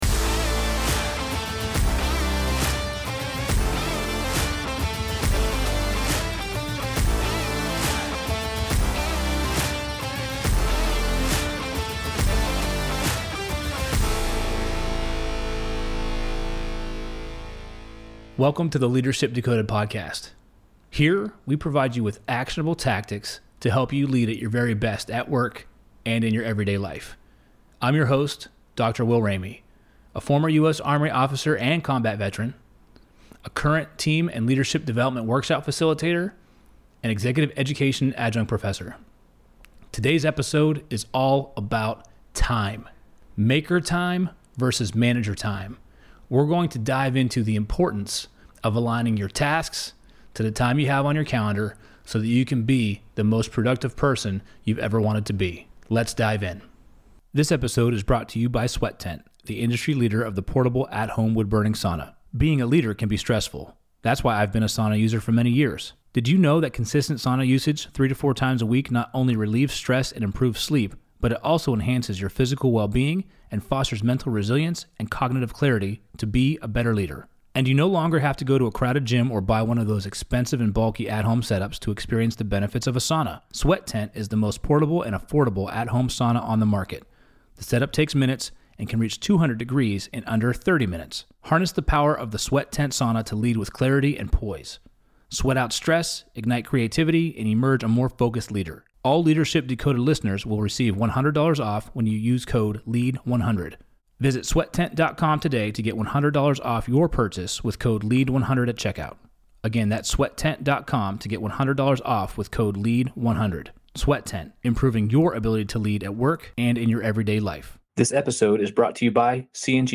in the Loop Internet studio